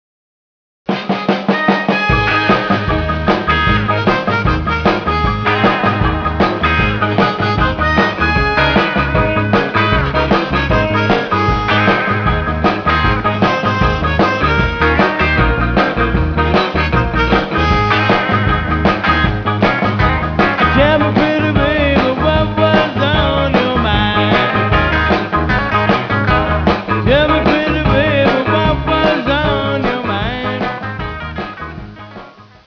Harmonica and vocals
Guitar
Electric Bass
Acoustic Bass
Drums
Piano